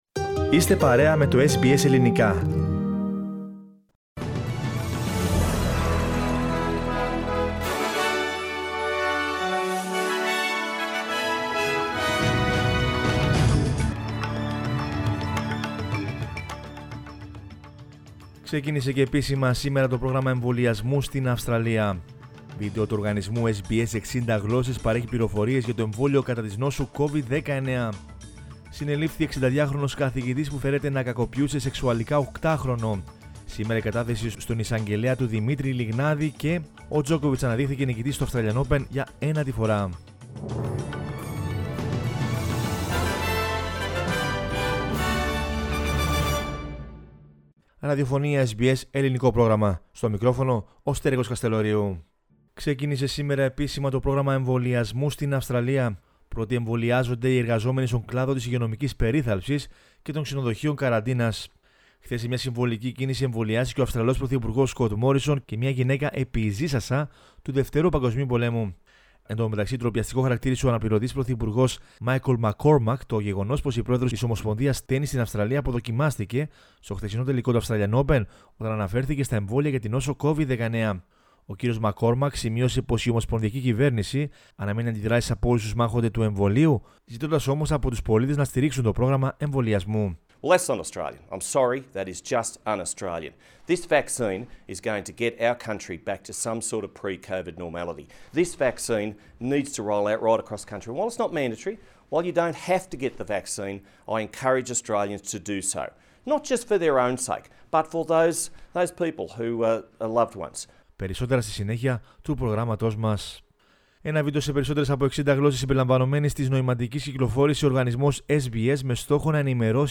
News in Greek from Australia, Greece, Cyprus and the world is the news bulletin of Monday 22 February 2021.